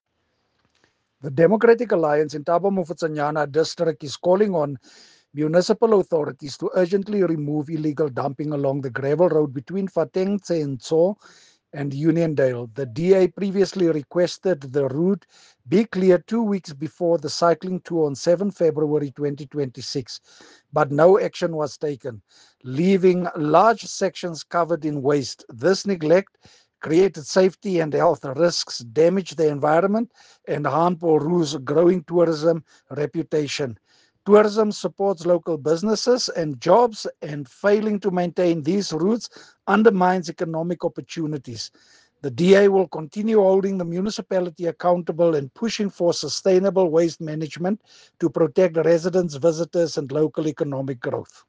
Afrikaans soundbites by Cllr Marius Marais and